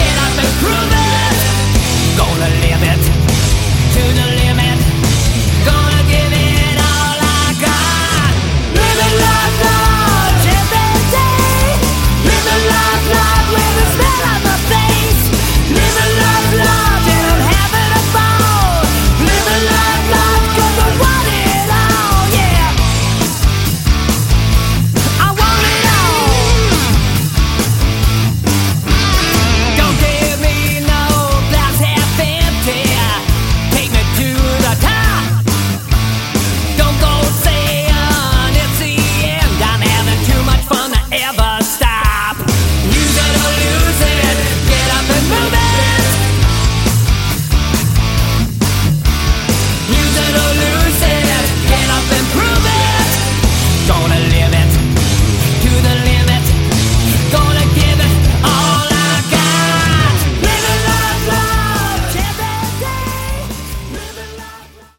Category: Hard Rock
Vocals
Bass
Drums
Guitar